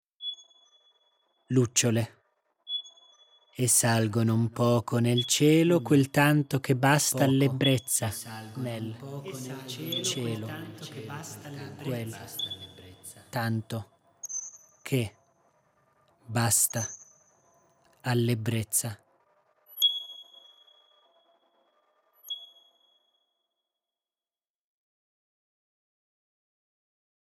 Sonorizzare delle poesie.